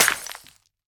Minecraft Version Minecraft Version 1.21.5 Latest Release | Latest Snapshot 1.21.5 / assets / minecraft / sounds / block / suspicious_gravel / break2.ogg Compare With Compare With Latest Release | Latest Snapshot
break2.ogg